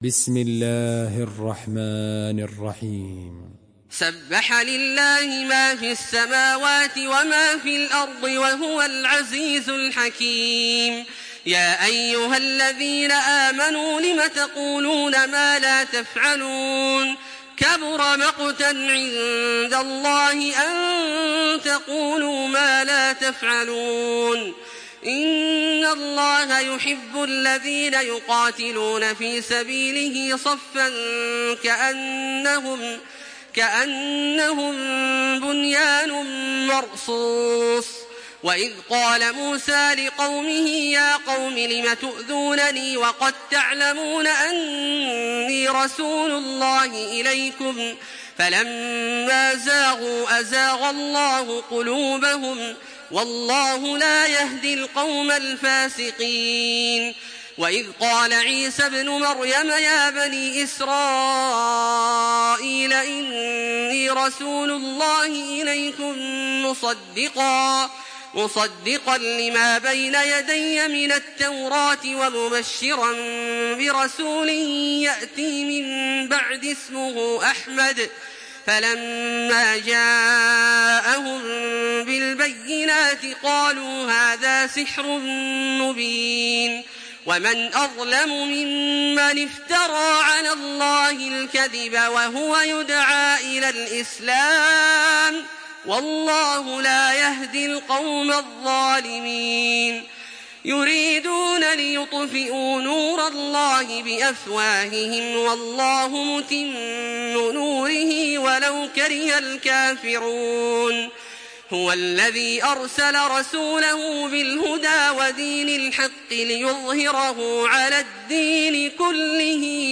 Surah As-Saf MP3 in the Voice of Makkah Taraweeh 1426 in Hafs Narration
Surah As-Saf MP3 by Makkah Taraweeh 1426 in Hafs An Asim narration.
Murattal Hafs An Asim